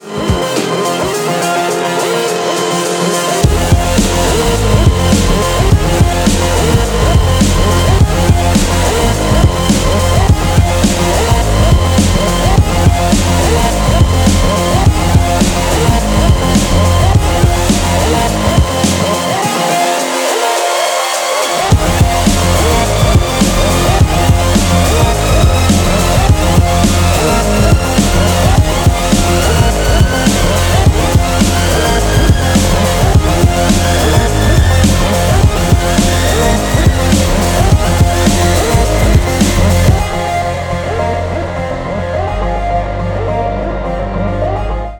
громкие
жесткие
мощные
Драйвовые
Electronic
без слов
electro
Дабстеп
Громкая электронная музыка